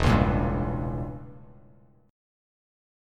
Edim7 chord